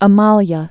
(ə-mälyə)